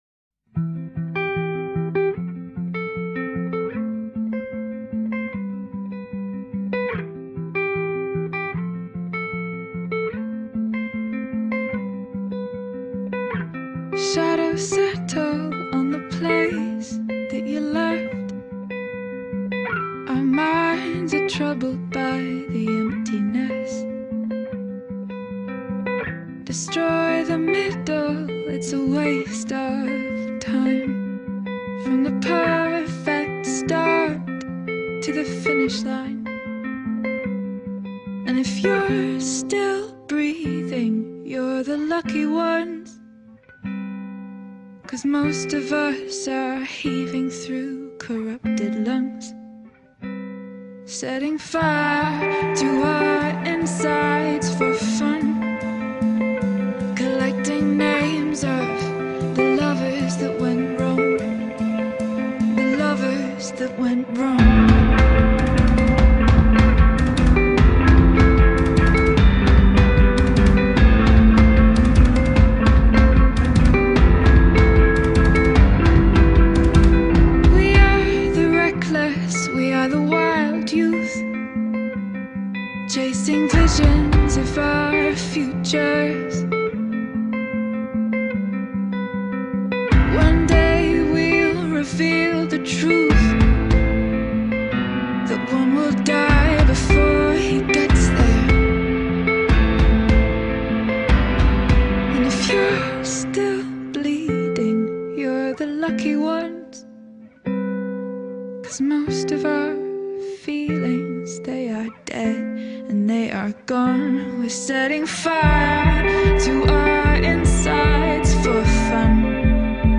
ایندی فولک